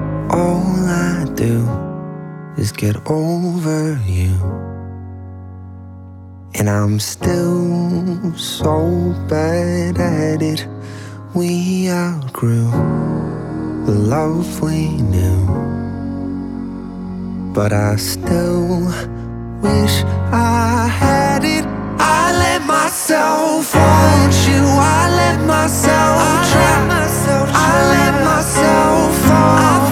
• Pop
is a piano-driven pop ballad